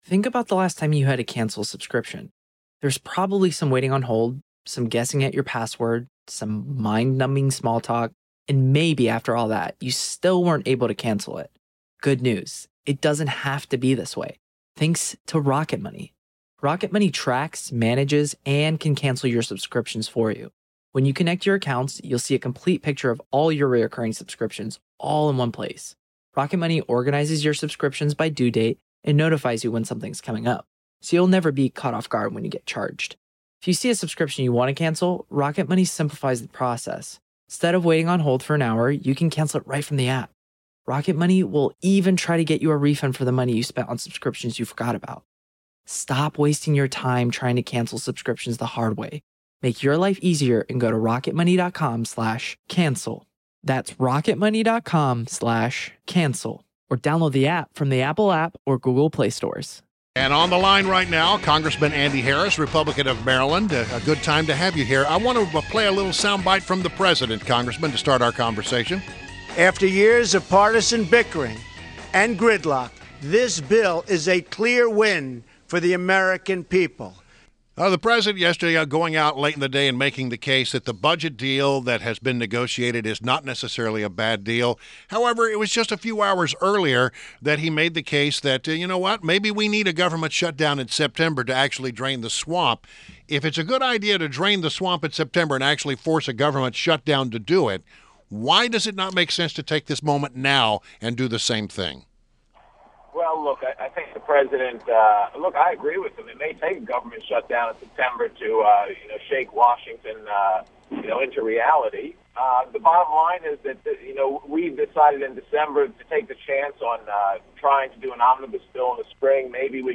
INTERVIEW — CONGRESSMAN ANDY HARRIS – R-MD (M.D.)